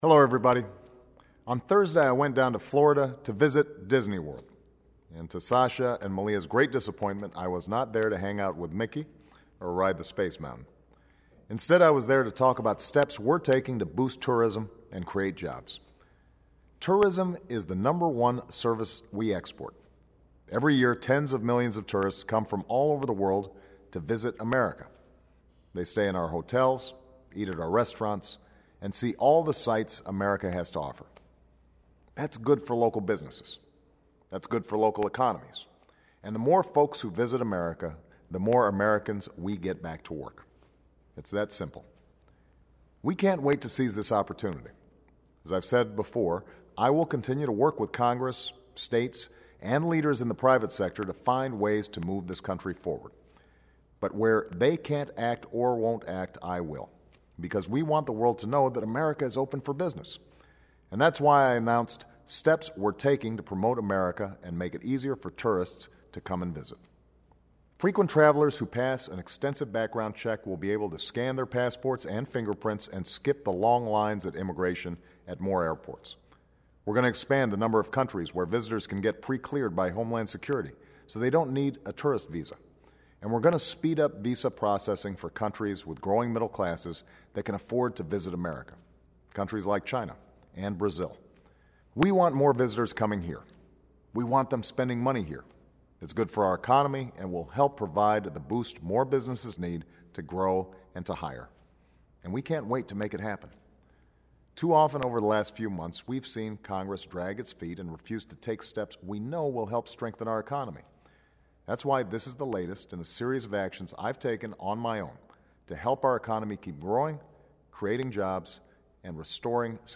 演讲听力材料01.23
Remarks of President Barack Obama